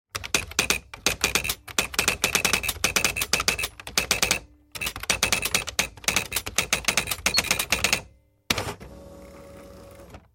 打字机 " 打字机 电动打字 01
描述：在电动打字机上打字很简单。用Zoom H4n和Rode NTG3进行了改装。